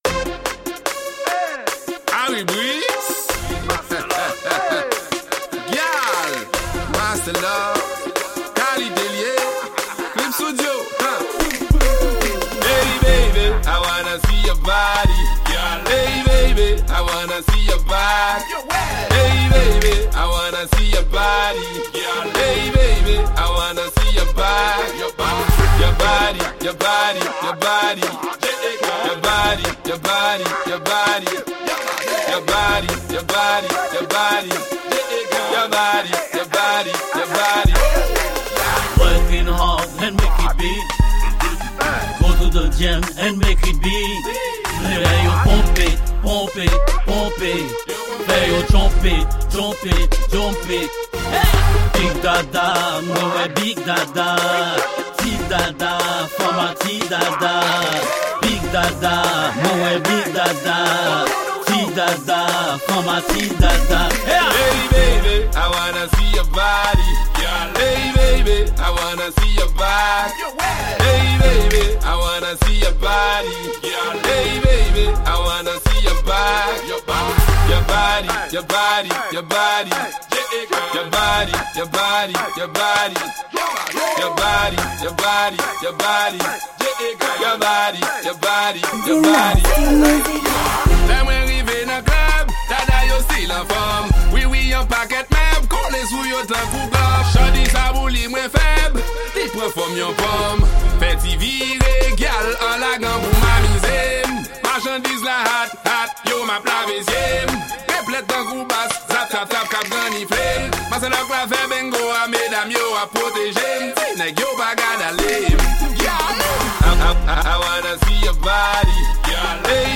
Genre: Dance hall.